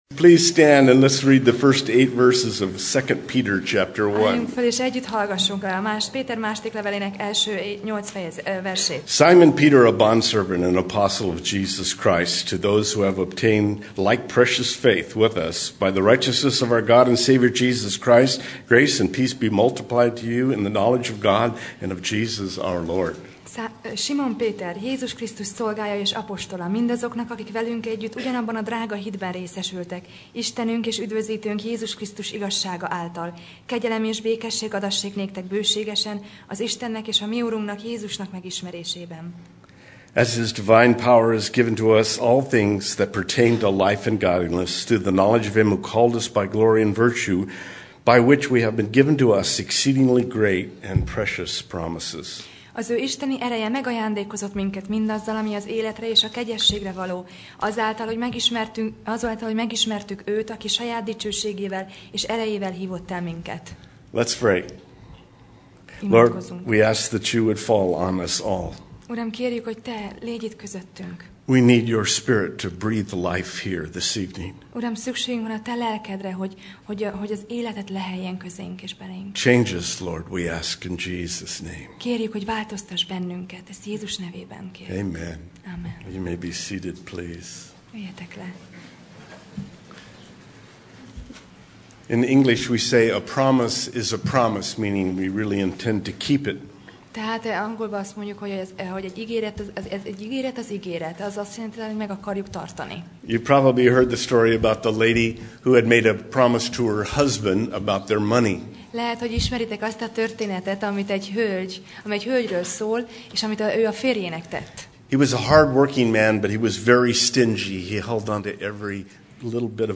Alkalom: Konferencia